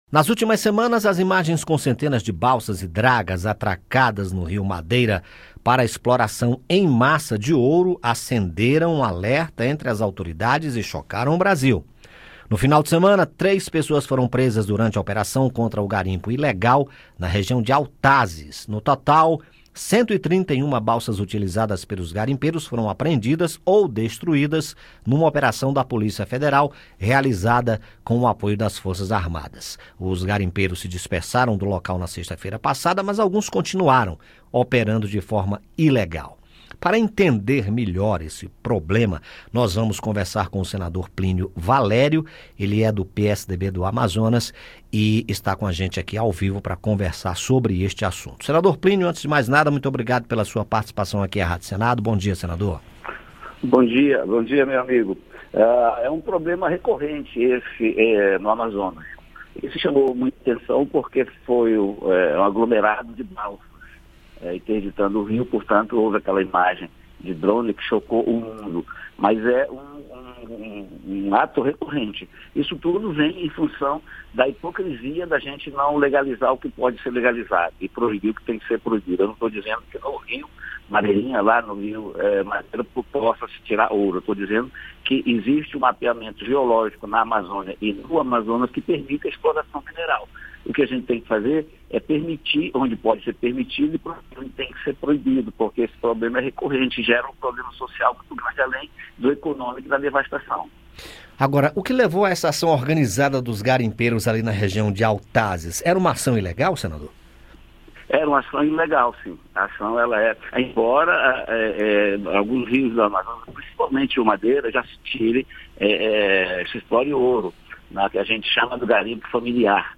Aqui você escuta as entrevistas feitas pela equipe da Rádio Senado sobre os assuntos que mobilizam o país e sobre as propostas que estão sendo discutidas no Parlamento.